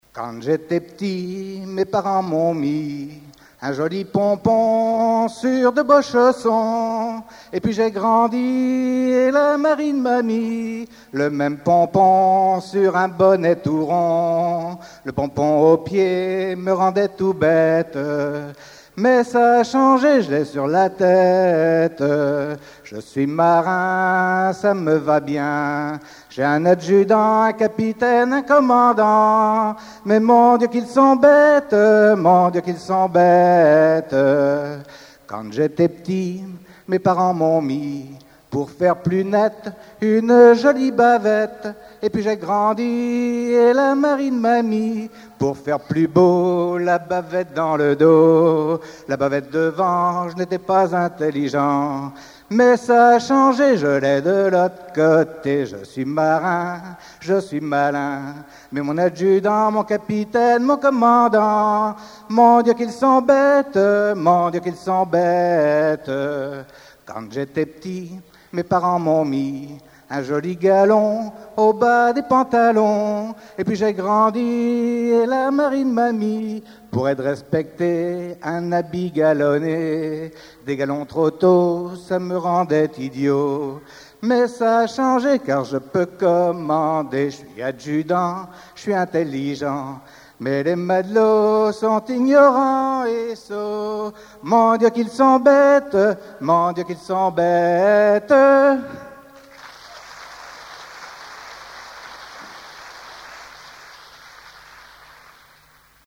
Genre strophique
Chansons de la soirée douarneniste 88
Pièce musicale inédite